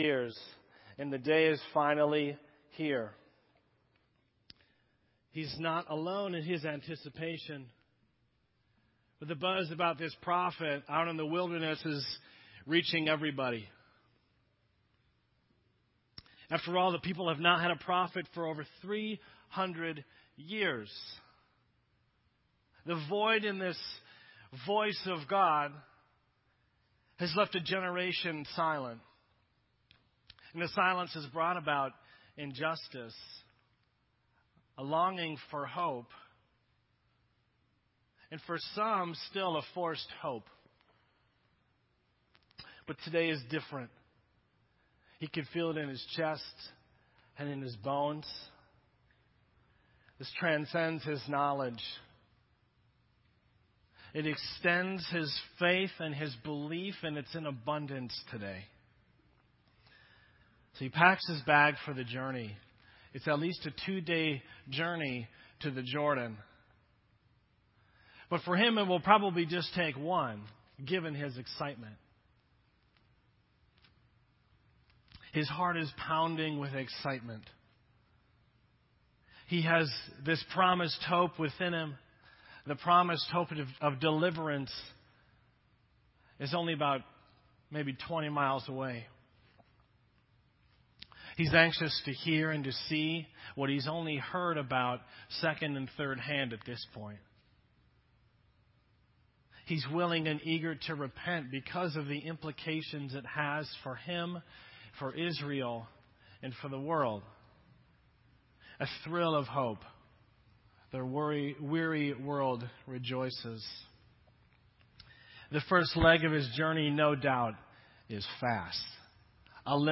This entry was posted in Sermon Audio on December 8